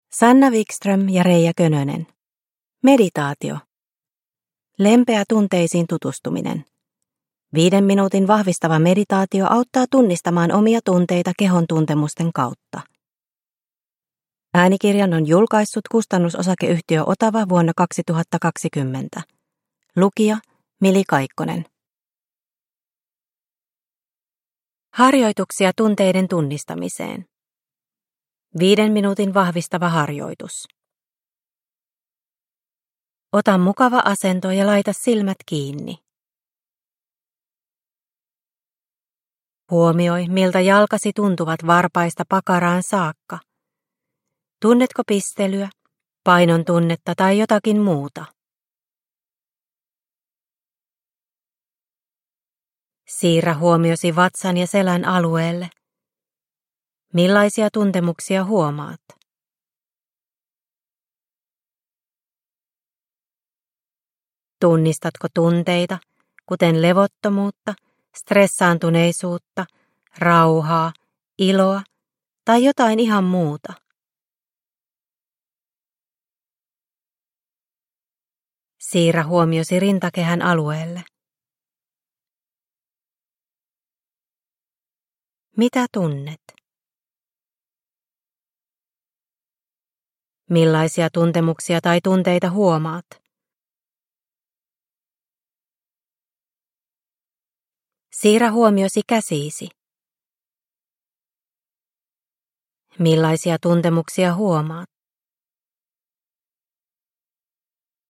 Meditaatio - Lempeä tunteisiin tutustuminen – Ljudbok – Laddas ner